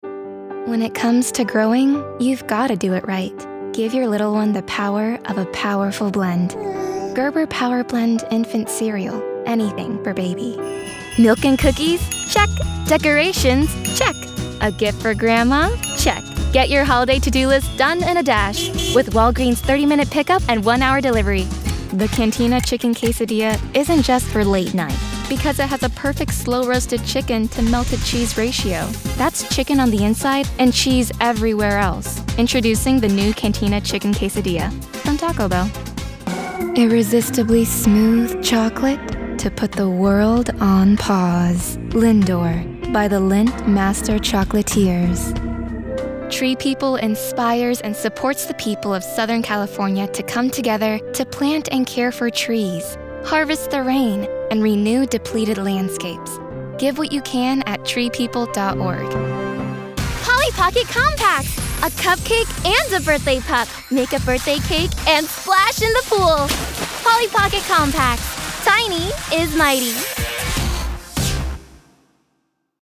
Child, Teenager, Young Adult
COMMERCIAL 💸
sincere
upbeat
broadcast level home studio